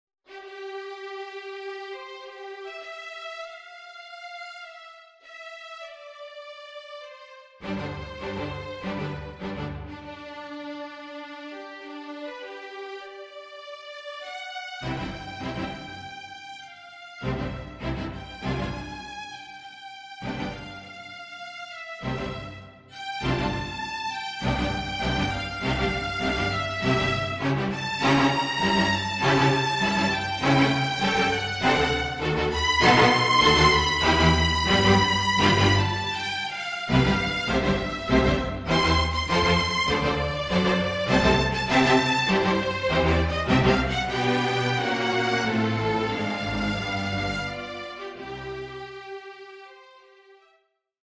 (Strings)